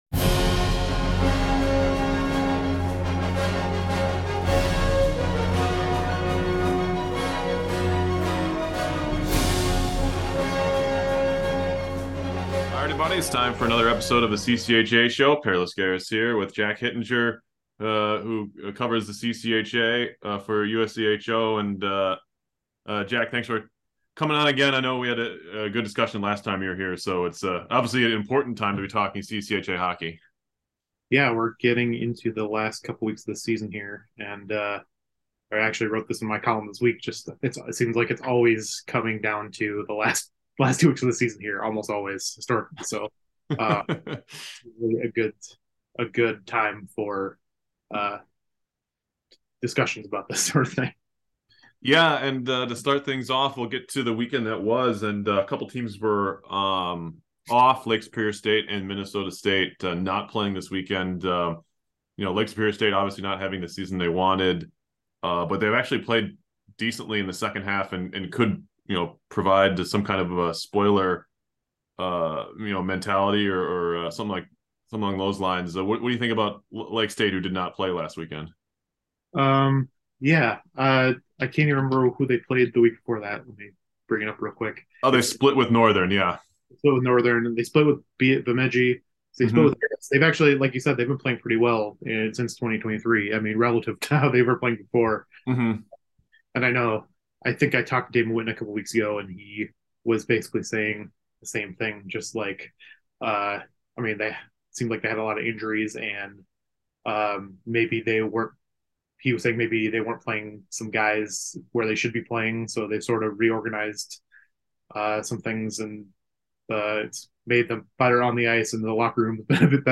stops by for a 1-on-1 interview.